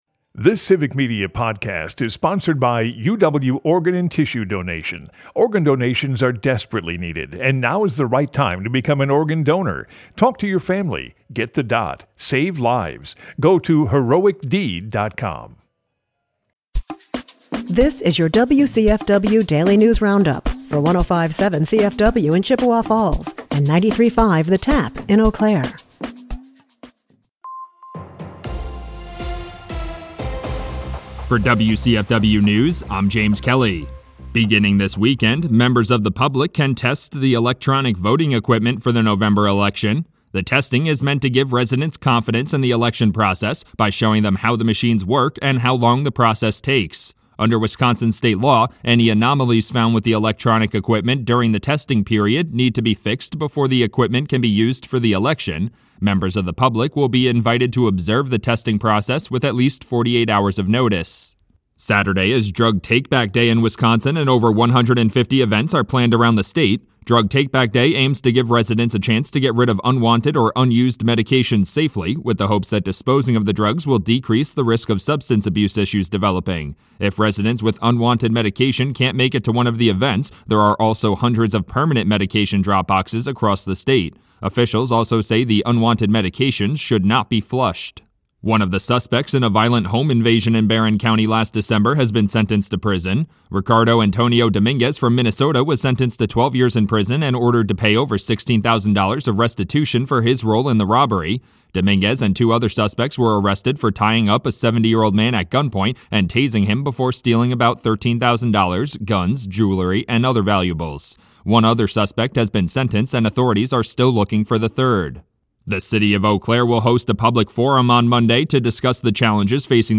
The WCFW Daily News Roundup has your state and local news, weather, and sports for Chippewa Falls, delivered as a podcast every weekday at 9 a.m. Stay on top of your local news and tune in to your community!